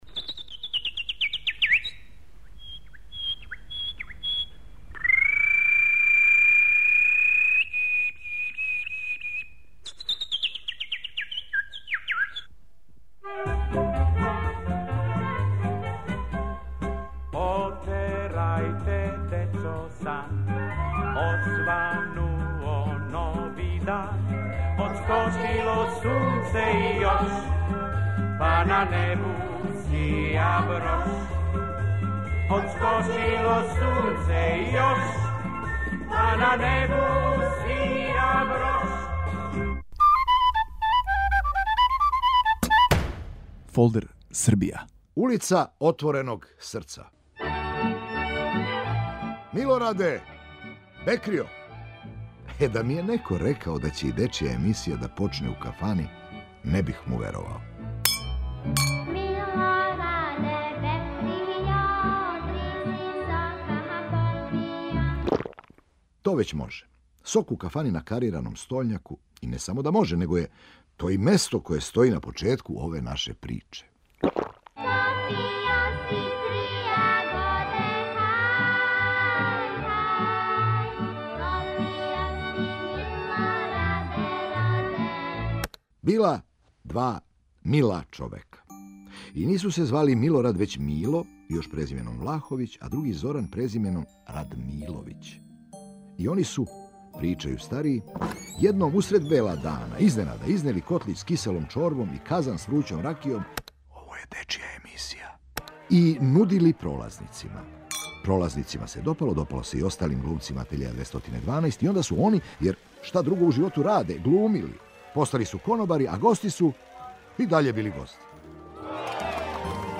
ваш водич кроз Србију: глумац Бранимир Брстина.